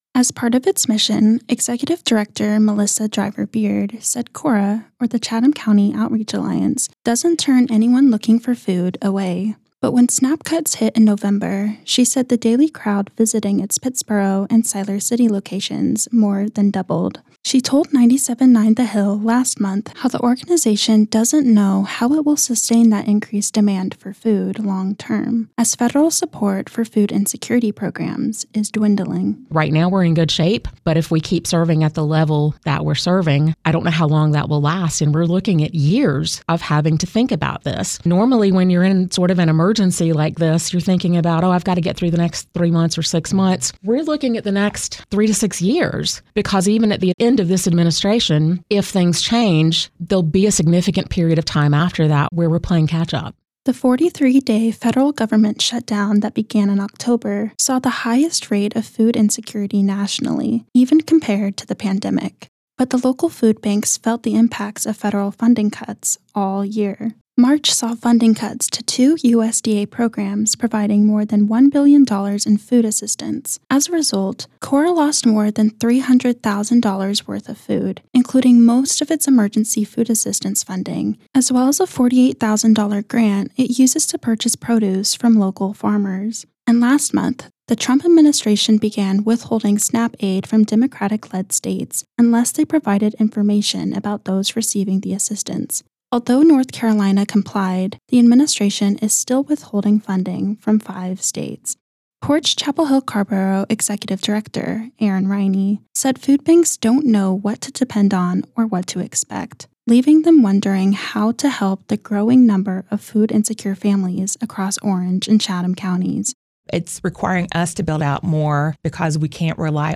Particularly following cuts to SNAP amid President Donald Trump’s administration changing federal funding norms, nonprofit organizations battling food insecurity in Chatham and Orange counties are struggling to sustain the increasing number of families who depend on them. In a conversation from last month’s Forum On The Hill series, local food banks shared how they are looking to the community for help in providing long term support.
Food Banks Panel_WRAP.mp3